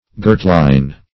Search Result for " girtline" : The Collaborative International Dictionary of English v.0.48: Girtline \Girt"line`\ (g[~e]rt"l[imac]n`), n. (Naut.) A gantline.